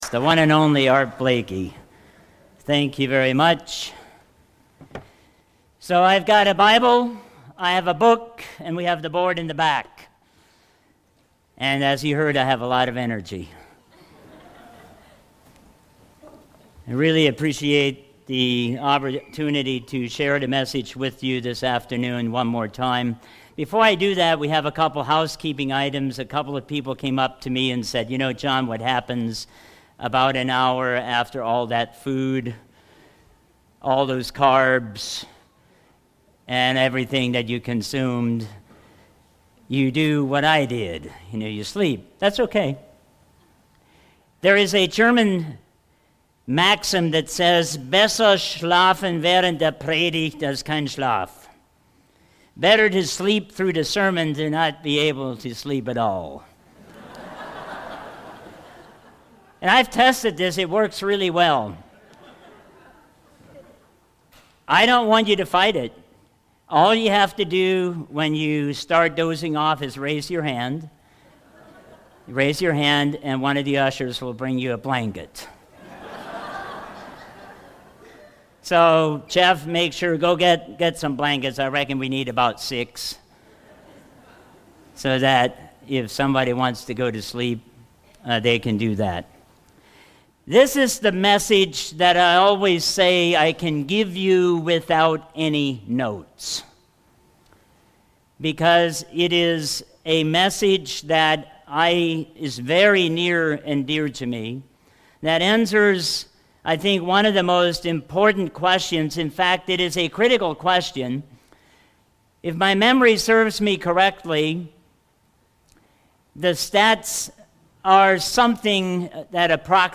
Given in Walnut Creek, Ohio